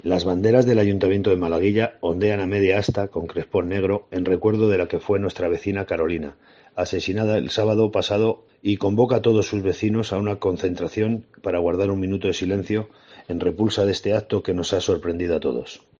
El alcalde de Malaguilla anima a sumarse a la concentración